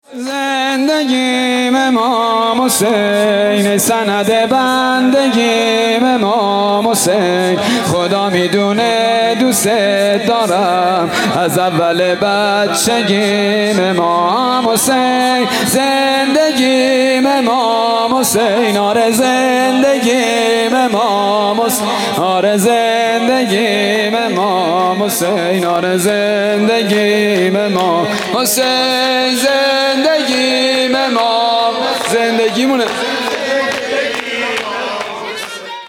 شب سوم محرم الحرام 1443
شور